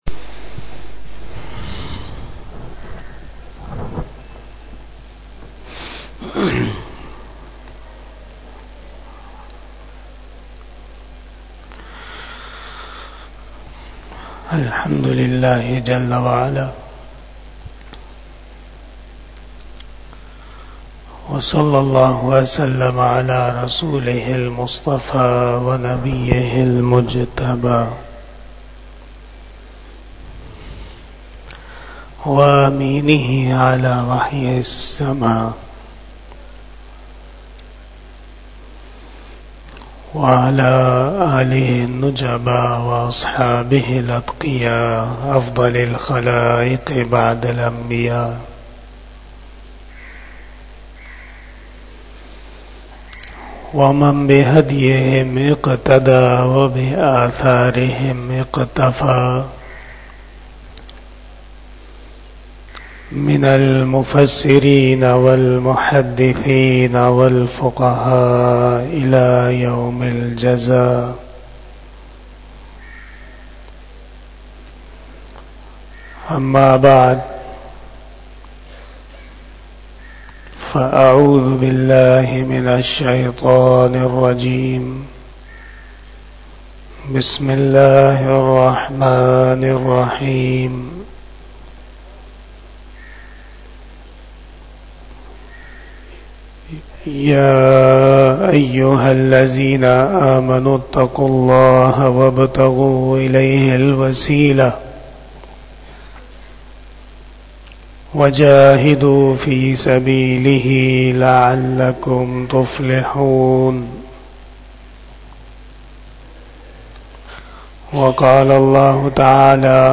Khitab-e-Jummah